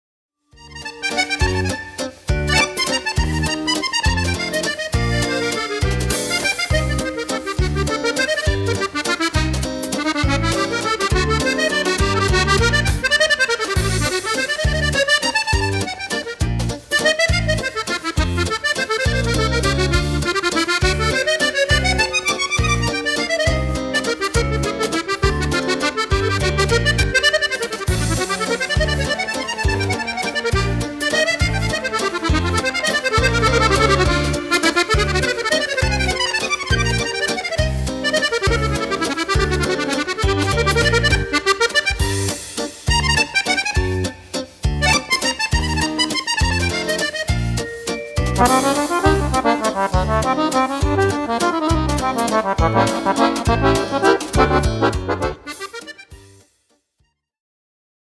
Valzer
Fisarmonica